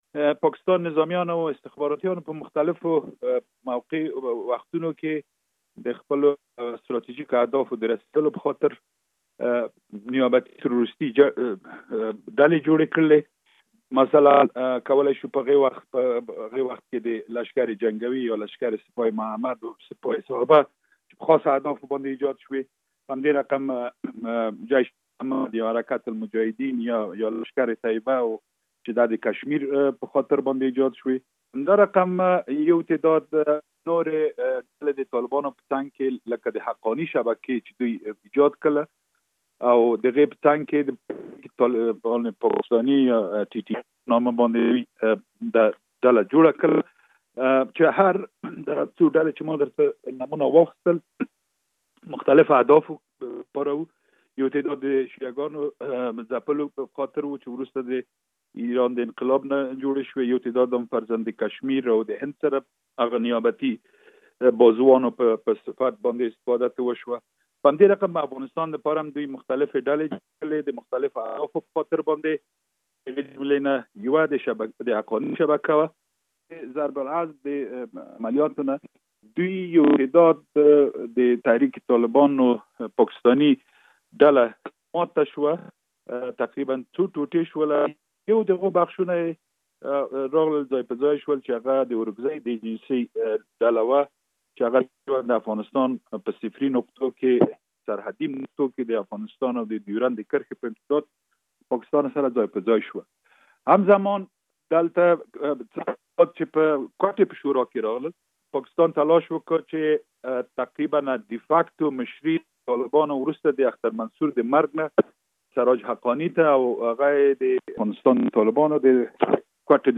د ملي امنیت له پخواني رئیس رحمت الله نبیل سره مرکه.